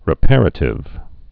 (rĭ-părə-tĭv) also re·par·a·to·ry (-tôrē)